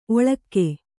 ♪ oḷakke